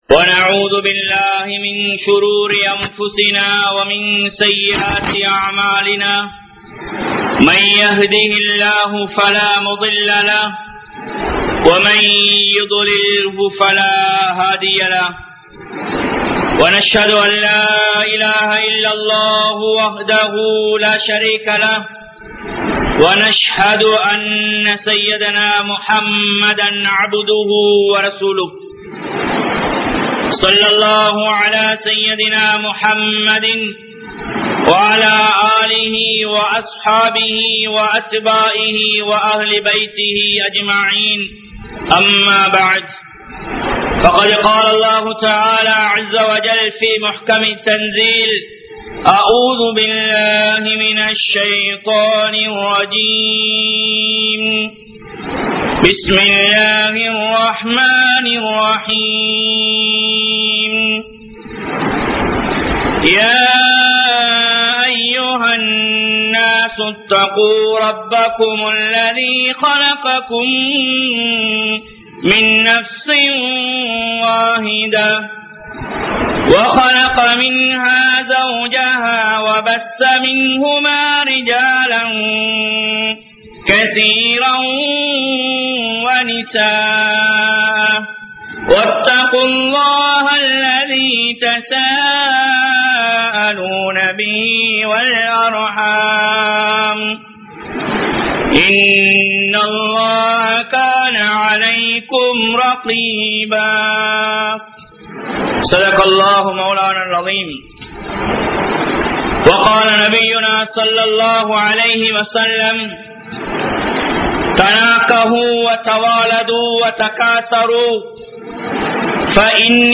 Athihariththu Sellum Vivaharaththuhal (அதிகரித்து செல்லும் விவாகரத்துகள்) | Audio Bayans | All Ceylon Muslim Youth Community | Addalaichenai